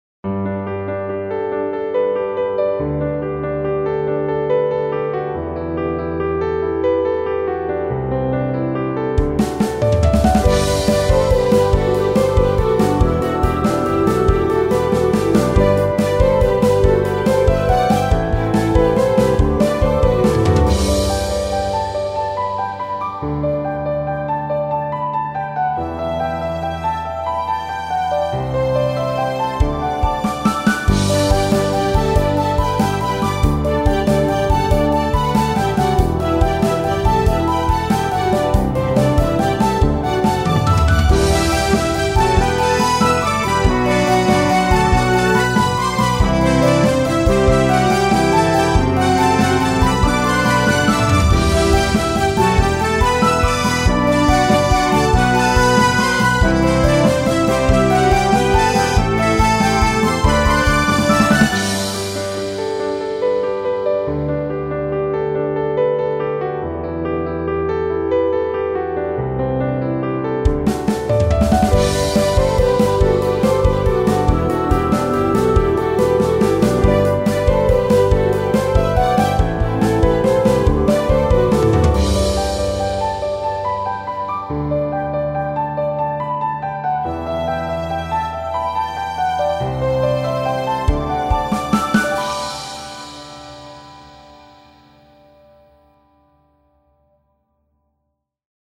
途中でストリングス等の楽器も入ってきて曲を盛り立てていきます。
ピアノを中心とした、綺麗な音が鳴り響く爽やかなBGMです。